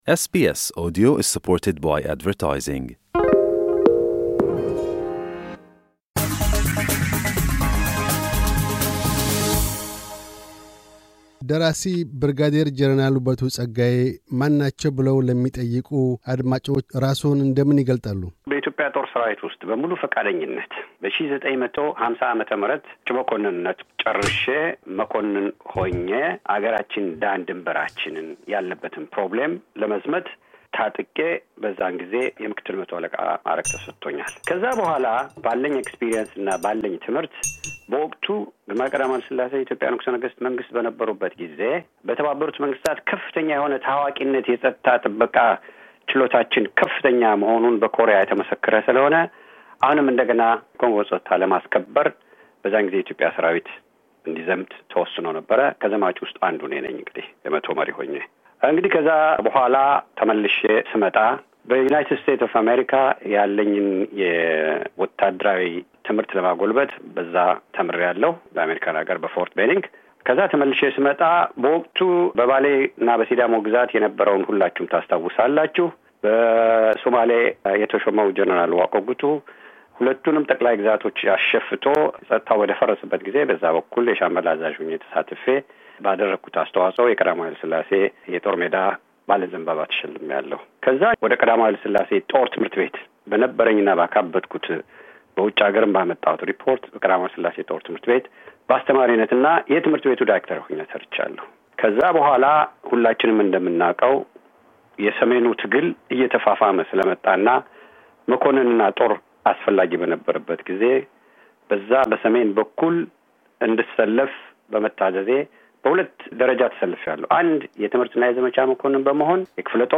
ዝክረ መታሰቢያ ይሆናቸውም ዘንድ ቀደም ሲል በወርኃ ኖቬምበር 2014 ያካሔድነውን ቃለ ምልልስ ደግመን አቅርበናል።